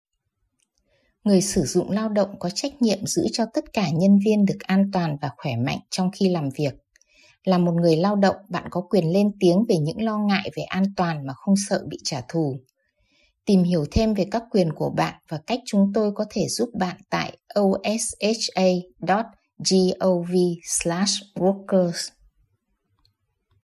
Workers’ Rights Wallet Cards and Public Service Announcements
workers-rights-psa-vietnamese.mp3